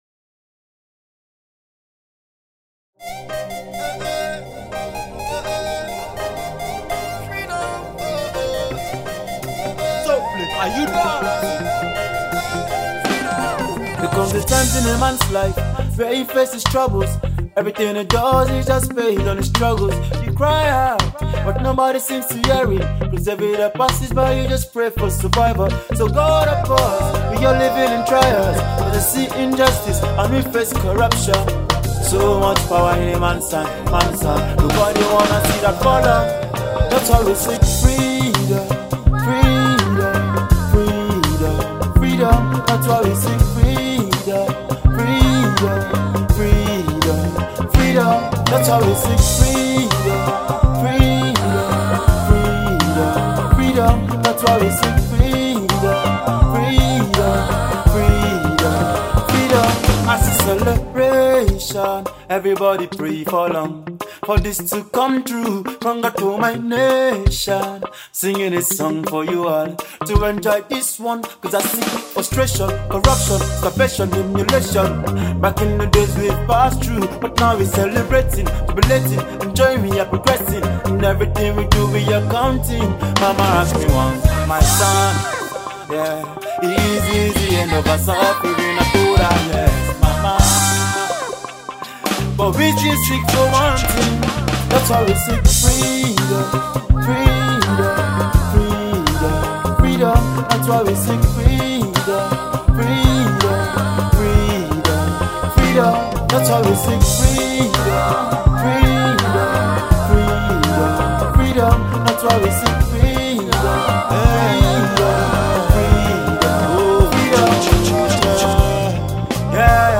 Reggae
Hood Reggae Music